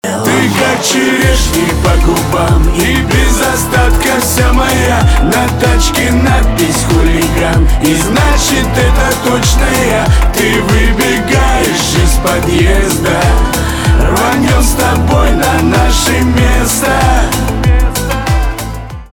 русский рэп
романтические , гитара
чувственные